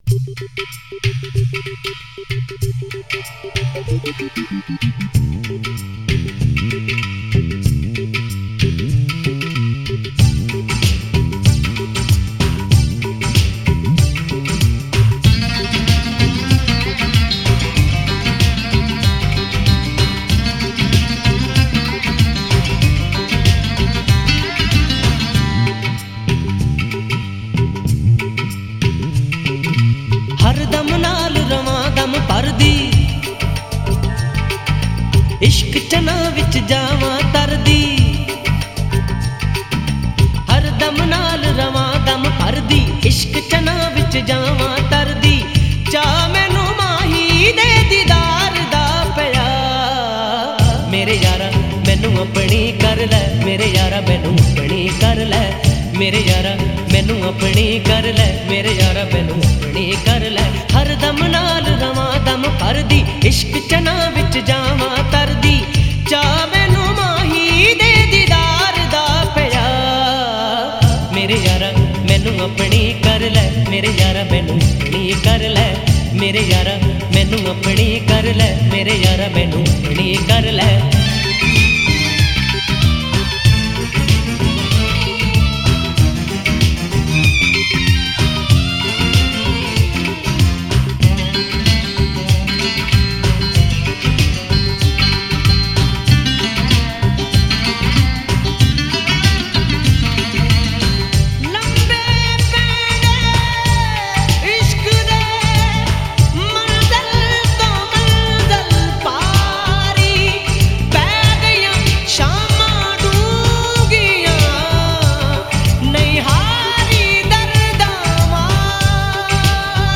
Punjabi Love Song